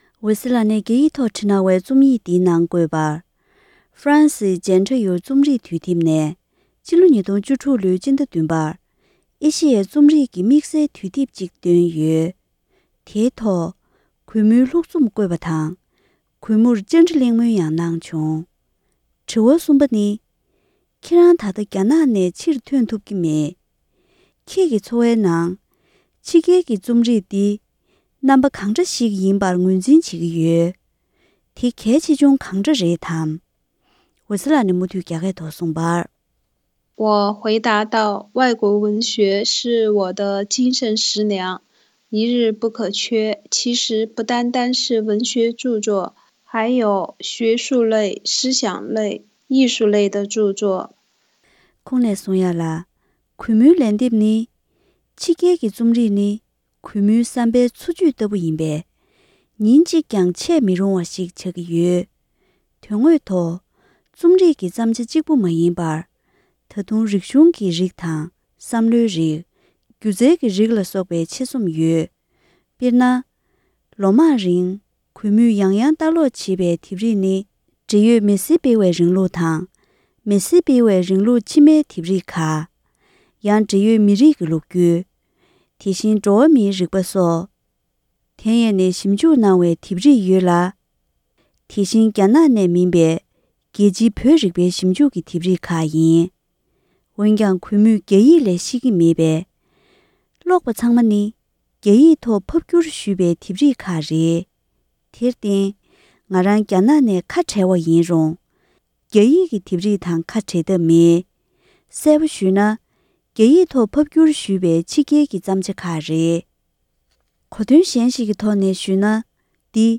ཧྥ་རན་སིའི་Jentayuདུས་དེབ་ནས་འོད་ཟེར་ལགས་སུ་བཅར་འདྲི།